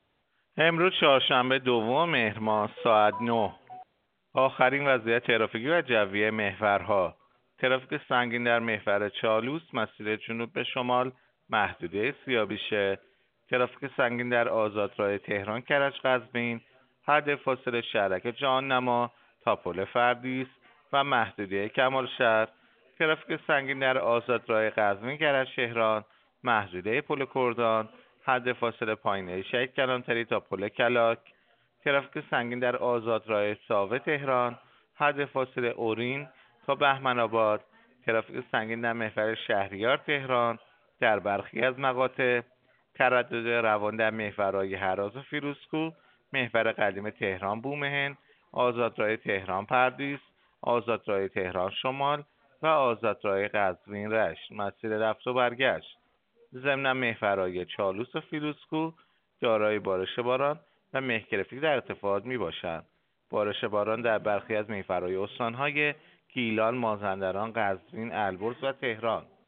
گزارش رادیو اینترنتی از آخرین وضعیت ترافیکی جاده‌ها ساعت ۹ دوم مهر؛